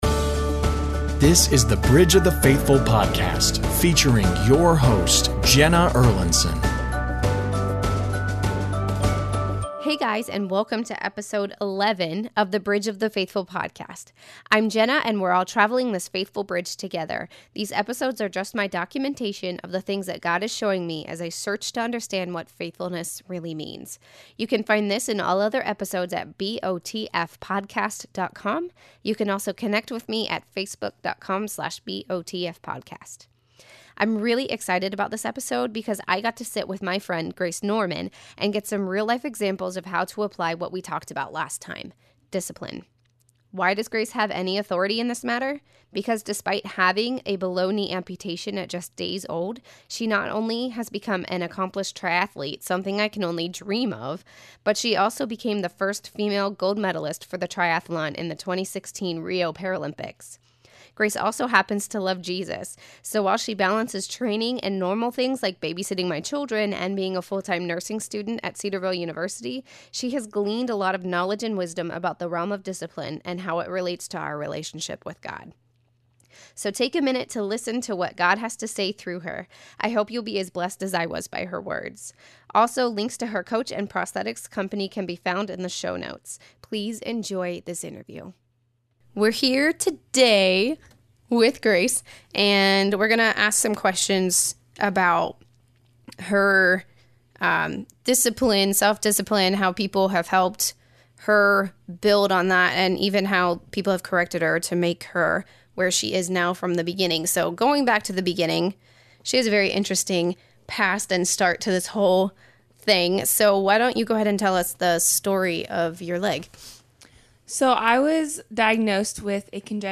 An Interview with Grace Norman